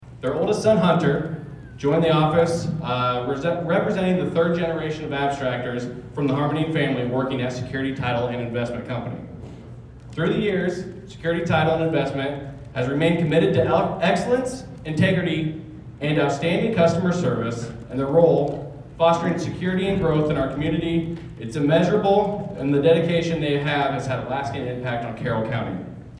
The Carroll Chamber of Commerce hosted its annual banquet Thursday evening and presented awards to community leaders, volunteers, and local businesses.